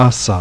Hissers: {sa.} [s]
UNIL-sound-s.aif